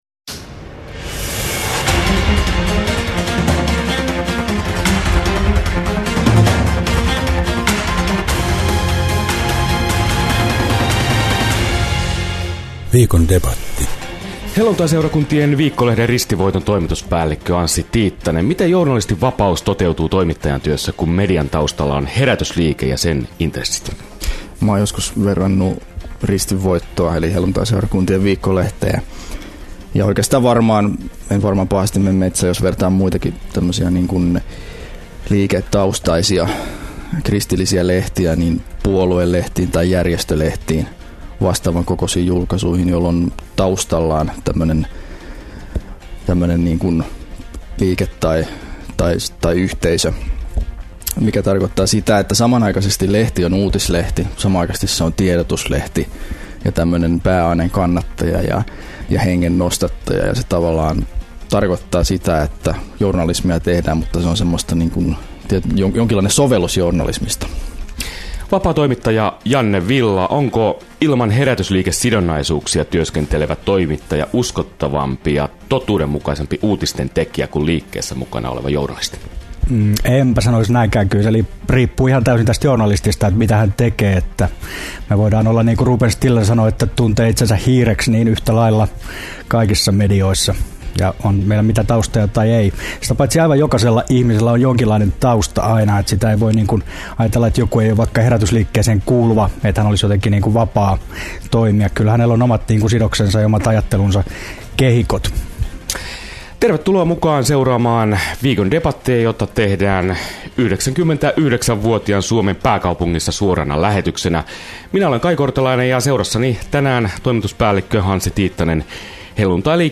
Keskustelu kuunneltavissa ohesta sekä uusintana Radio Dein taajuuksilla sunnuntaina 11.12. klo 20.